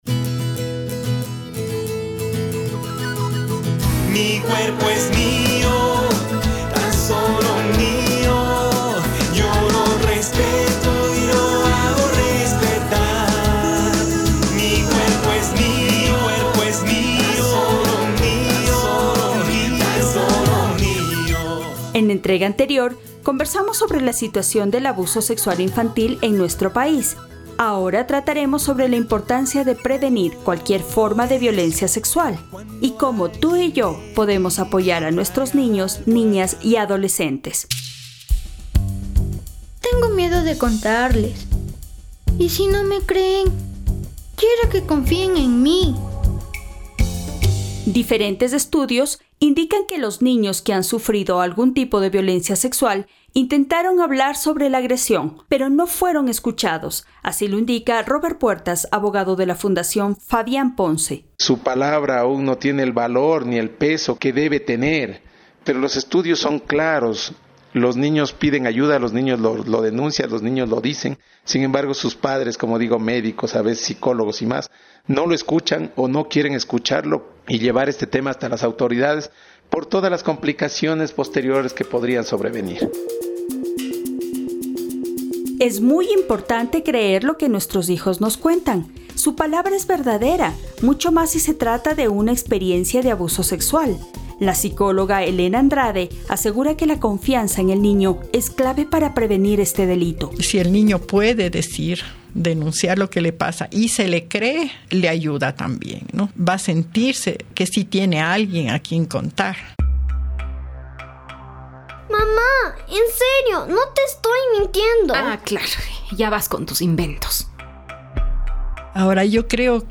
La Facultad Latinoamericana de Ciencias Sociales, FLACSO, realizó un Concurso Nacional de Periodismo Radiofónico: “La radio se toma la palabra”.